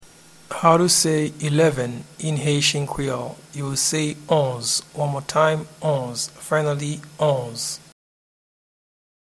Pronunciation and Transcript:
Eleven-in-Haitian-Creole-Onz-pronunciation.mp3